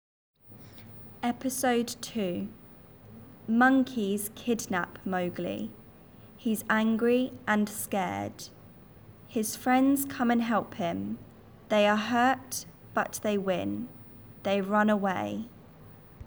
Episode 2 lent